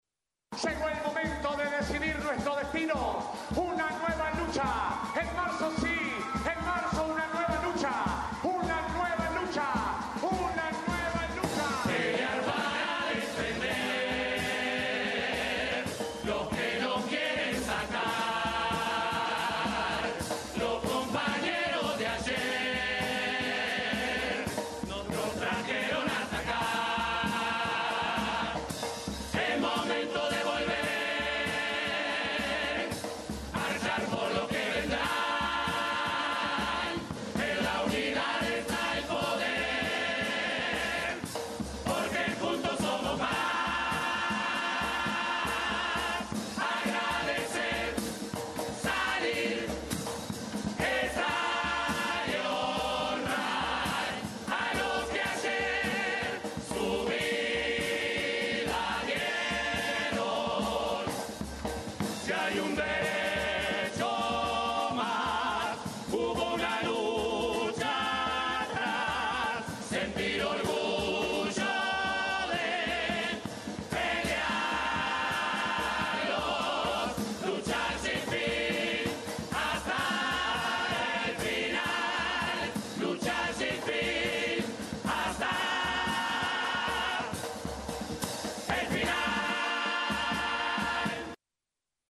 Asaltantes con Patente fue la primera murga en presentarse tras las postergaciones de lunes y martes en el Teatro de Verano, por Covid-19 y situación climática respectivamente.